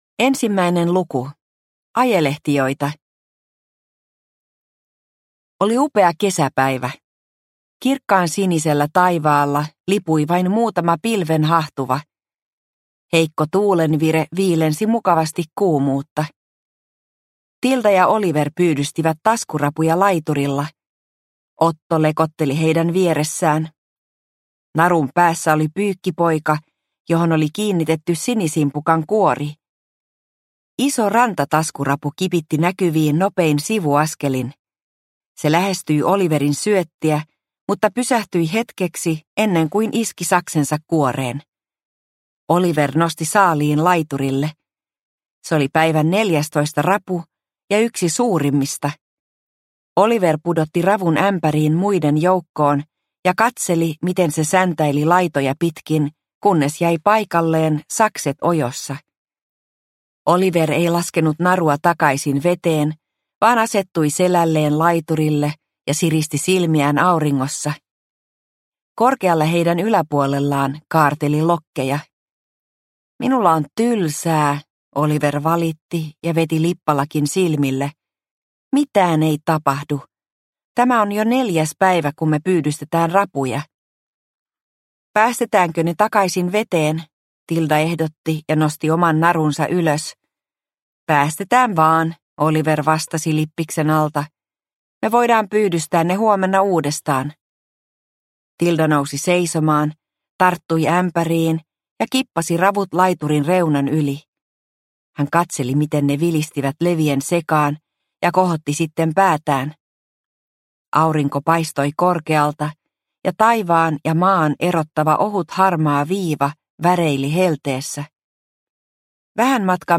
Operaatio Kesäsaari – Ljudbok – Laddas ner